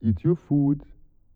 meldewesen-eat-food.wav